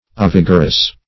Meaning of ovigerous. ovigerous synonyms, pronunciation, spelling and more from Free Dictionary.
ovigerous.mp3